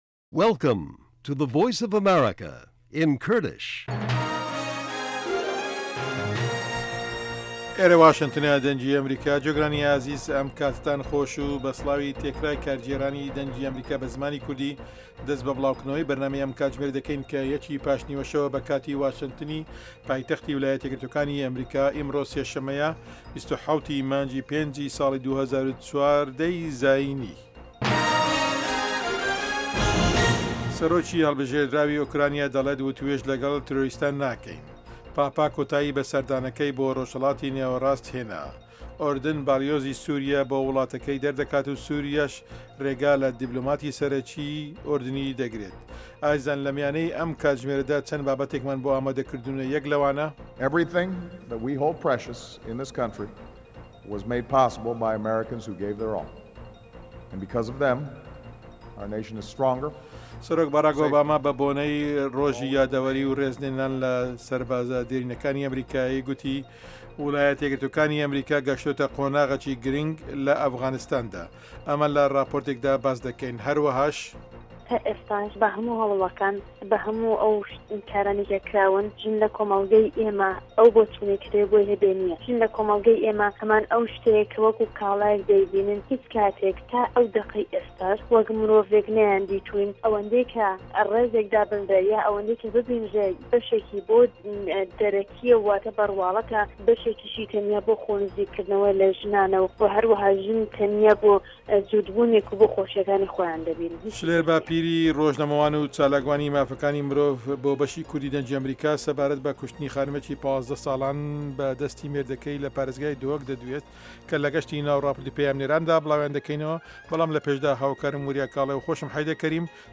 بابه‌ته‌كانی ئه‌م كاتژمێره بریتین له هه‌واڵه‌كان، ڕاپۆرتی په‌یامنێران، گفتوگۆ و شیكردنه‌وه، ئه‌مڕۆ له مێژووی ئه‌مه‌ریكادا، هه‌روههاش بابهتی ههمهجۆری هونهری، زانستی و تهندروستی، ئابوری، گهشتێك به نێو ڕۆژنامه جیهانییهكاندا، دیدوبۆچونی واشنتۆن، گۆرانی كوردی و ئهمهریكی و بهرنامهی ئهستێره گهشهكان له ڕۆژانی ههینیدا.